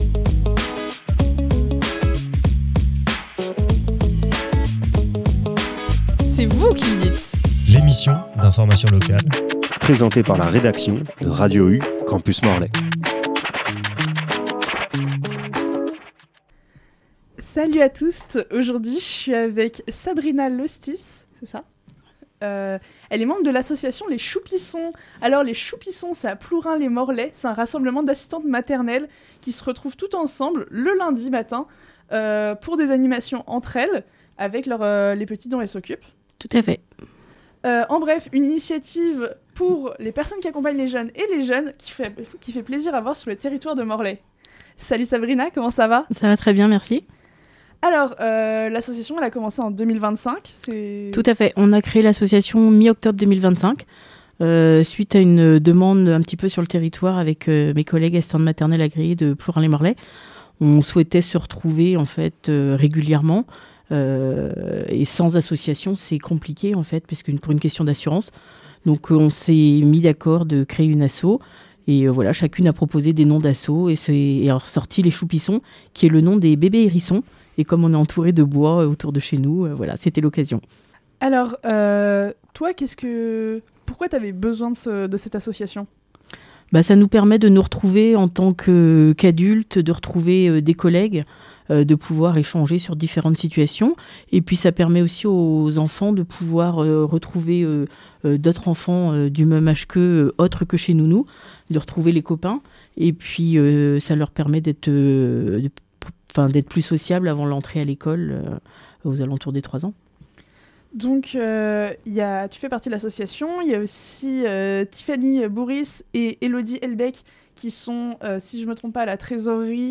itw_choupissons.mp3